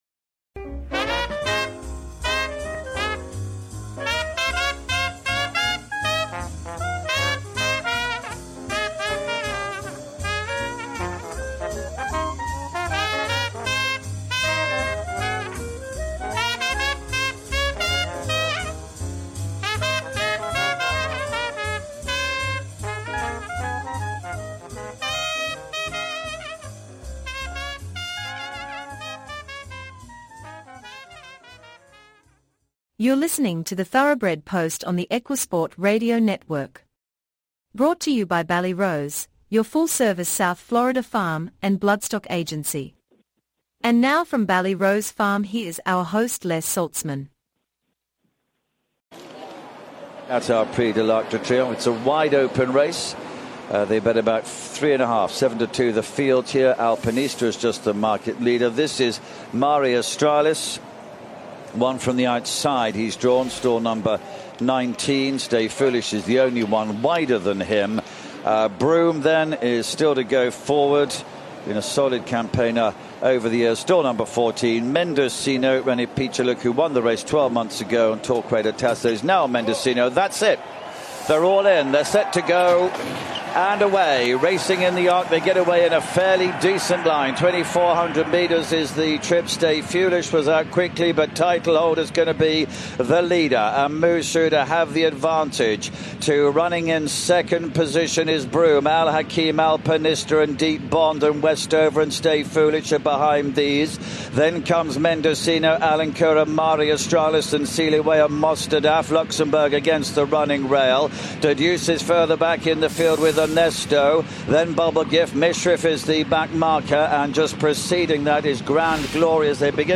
Guest, Sir Mark Prescott, 3rd Baronet (born 1948), is an English race horse trainer with over 2000 winners to his name